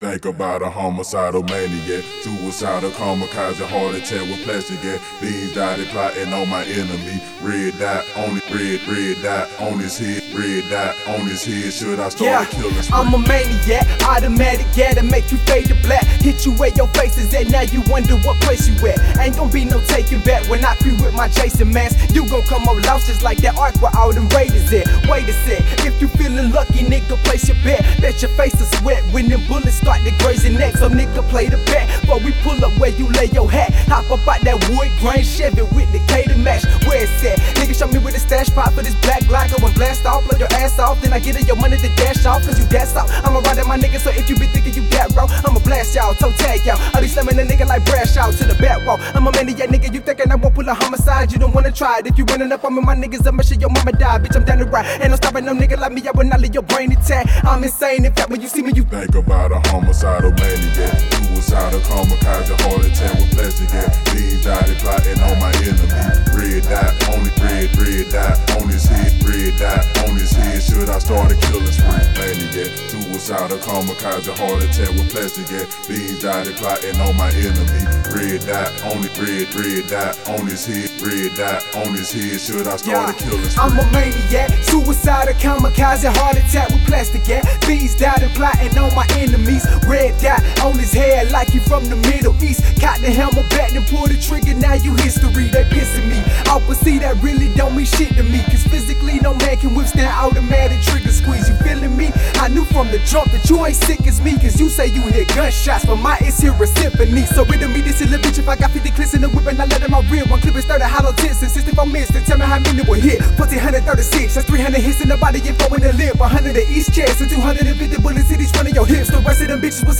Genre: Southern Rap.